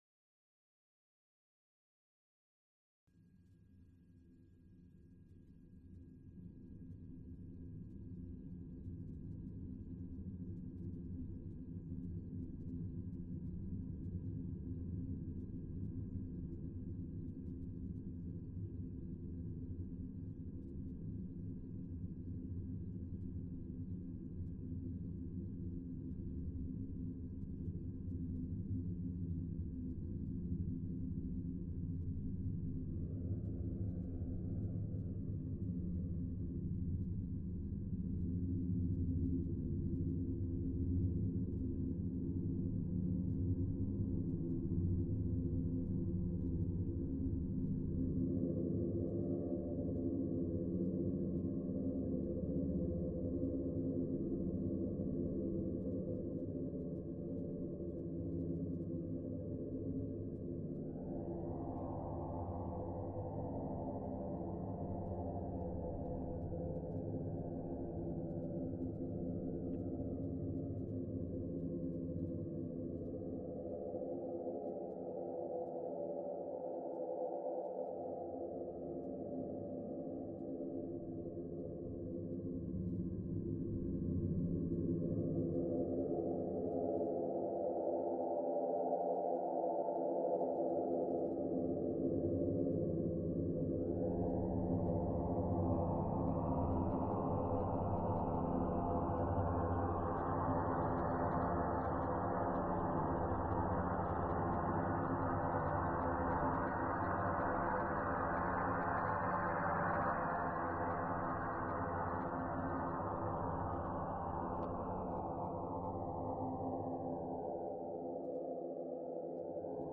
Sonification
Accompanies the exhibition.